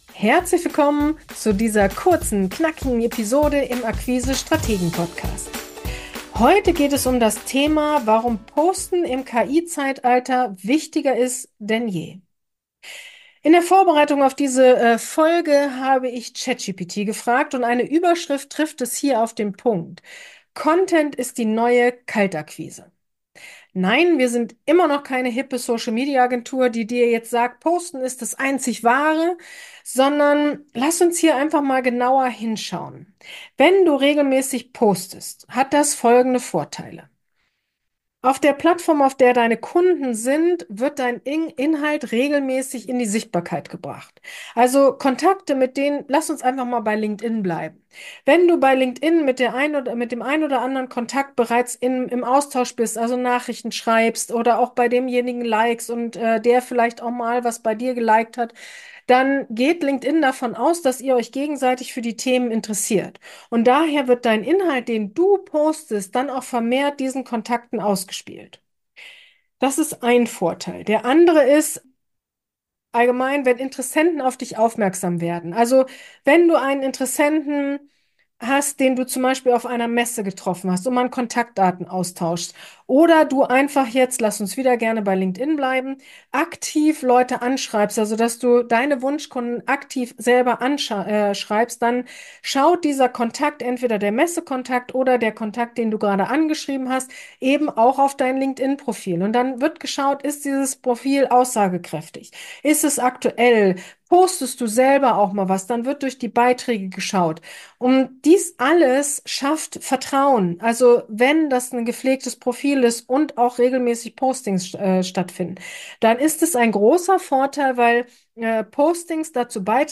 Vertrieb im Wandel | Interview